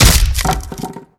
BaseballBatBreak.wav